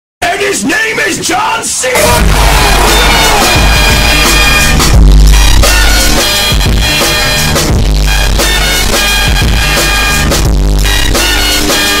Play, download and share john cena LOUD original sound button!!!!
john-cena-loud.mp3